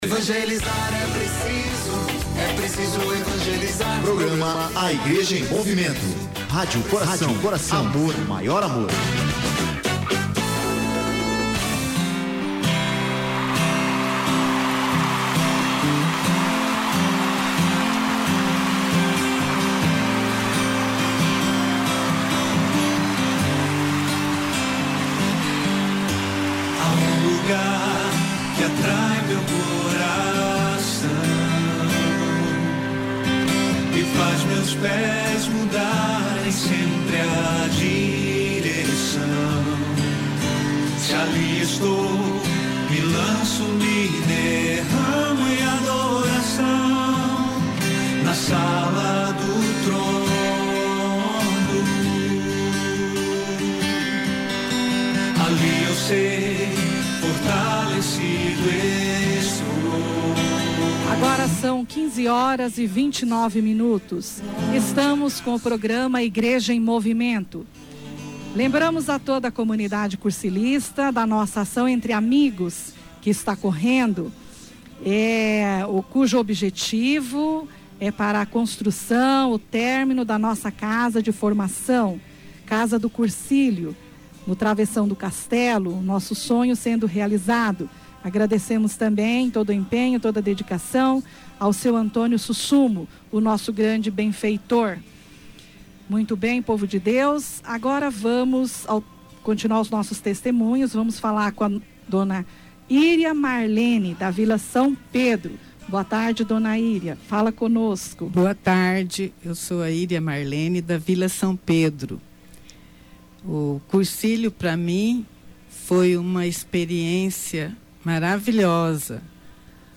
Cursistas do 114º Cursilho para Adultos da Diocese de Dourados partilharam no sábado (08/04) durante o programa 'A Igreja em Movimento' testemunhos edificantes e emocionantes para nossos ouvintes.